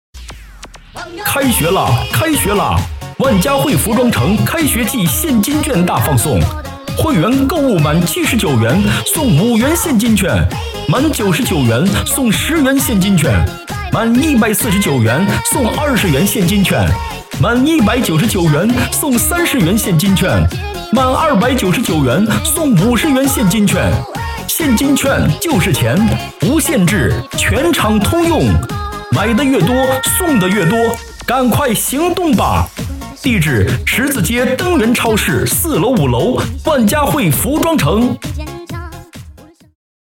【男80号促销】万嘉惠服装城.mp3